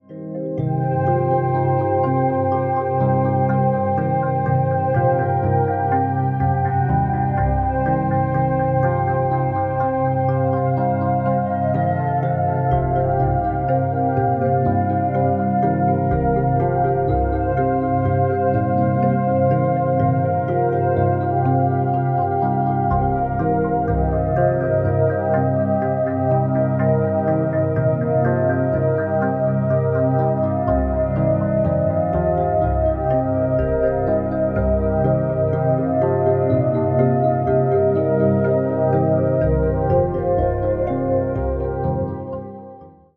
62 bpm
12-string LucyTuned guitar